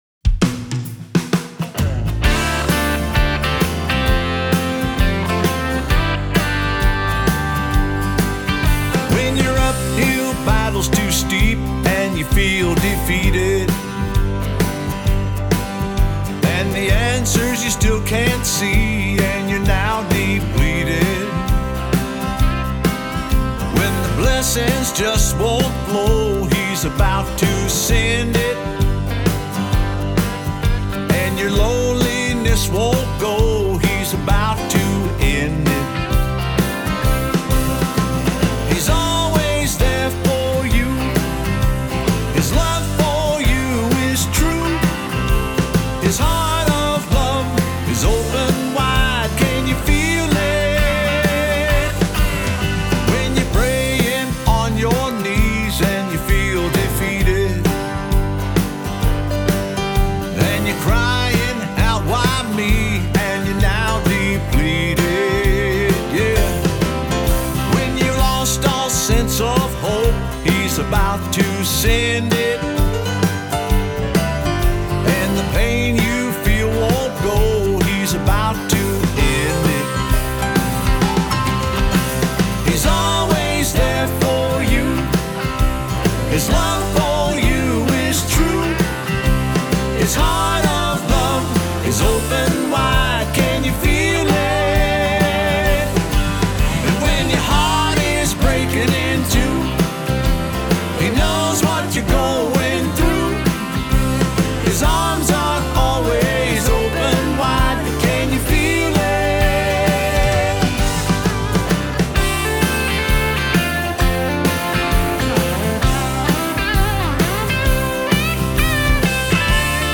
encouraging and uplifting